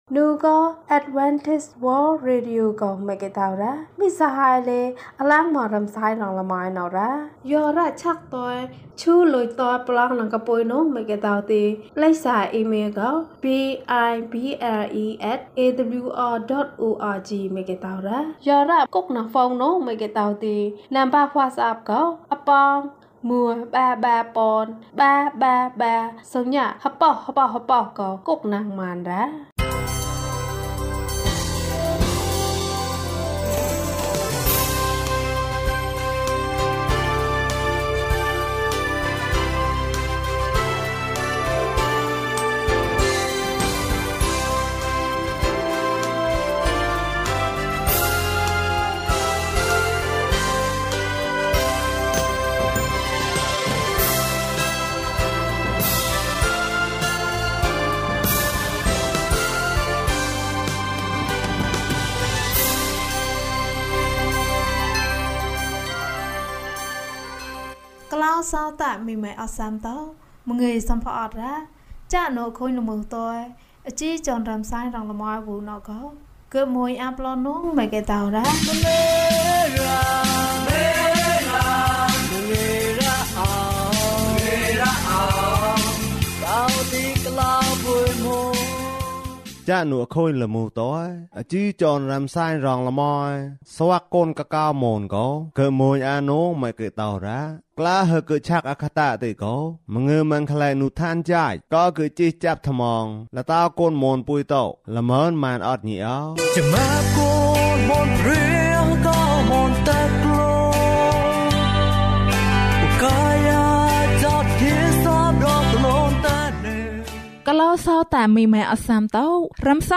ခရစ်တော်ထံသို့ ခြေလှမ်း။၃၀ ကျန်းမာခြင်းအကြောင်းအရာ။ ဓမ္မသီချင်း။ တရားဒေသနာ။